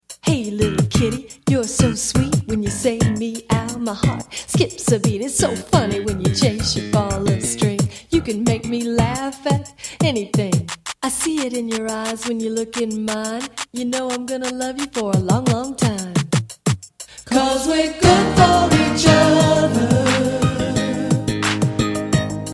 Animal Song Lyrics and Soundclip for Children